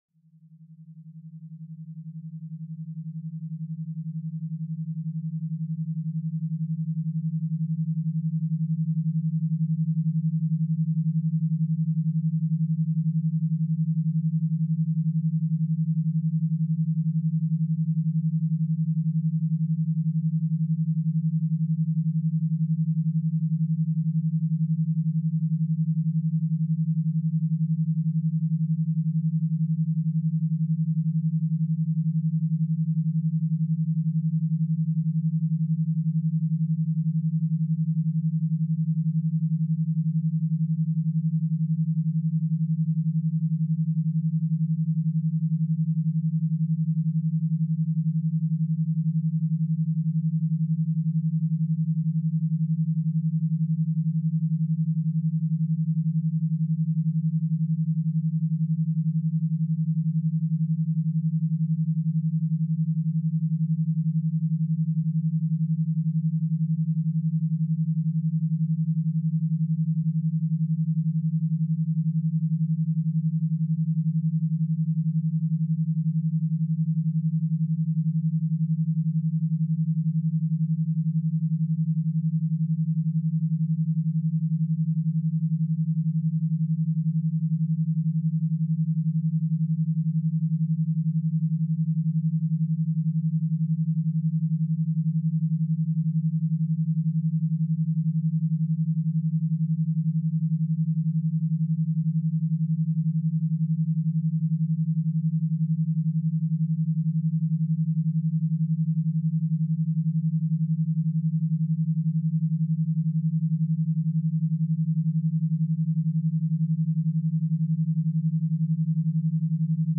Nuit pluvieuse douce · méthode scientifique 50-10 pour focus prolongé